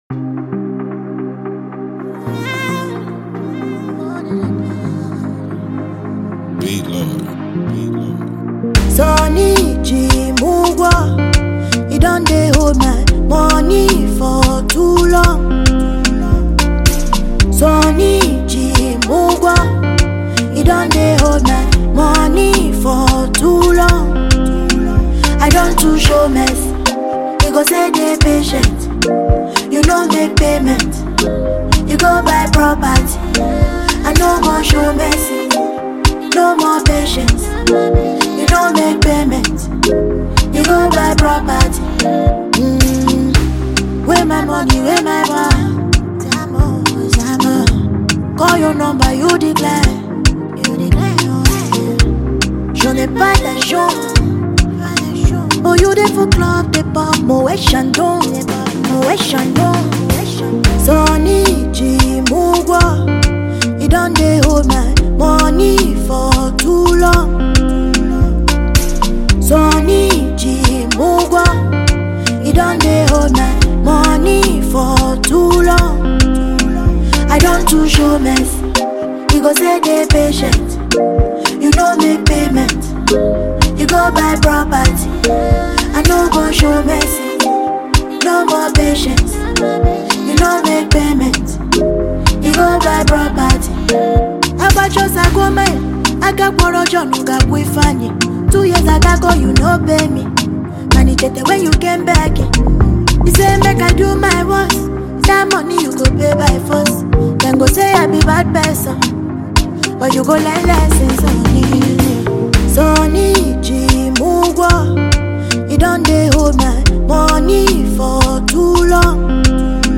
Well renowned Female Nigerian artist and performer
gbedu song